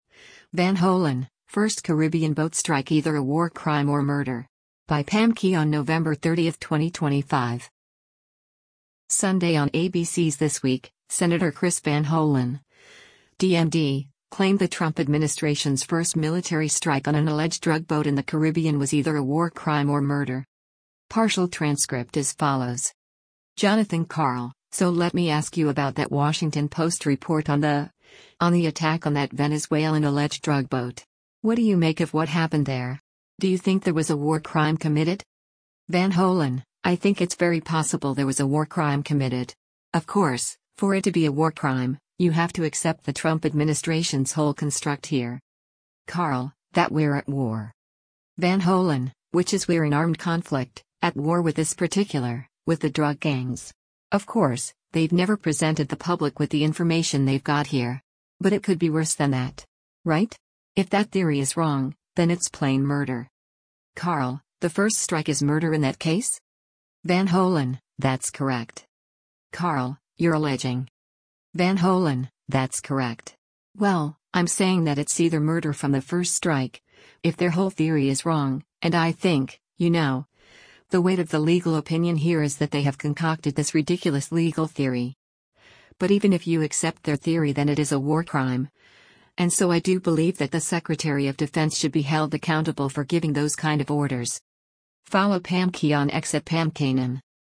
Sunday on ABC’s “This Week,” Sen. Chris Van Hollen (D-MD) claimed the Trump administration’s first military strike on an alleged drug boat in the Caribbean was either a “war crime” or “murder.”